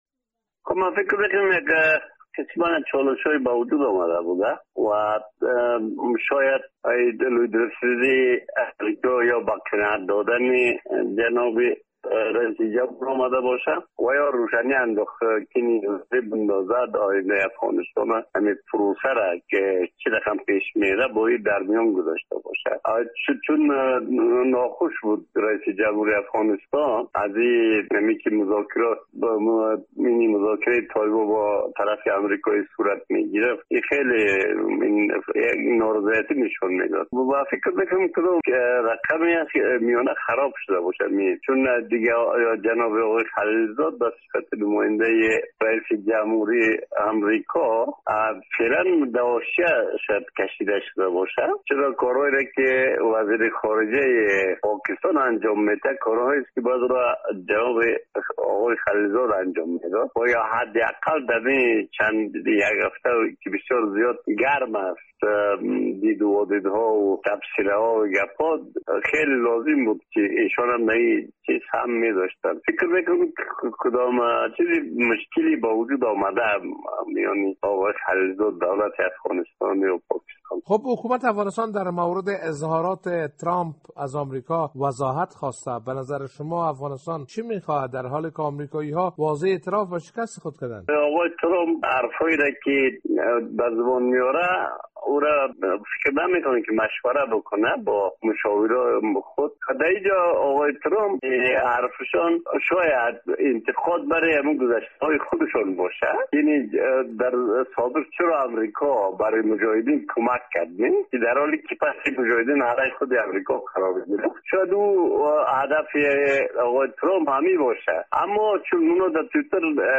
کارشناس سیاسی و روزنامه نگار افغان
گفت و گو با خبرنگار رادیو دری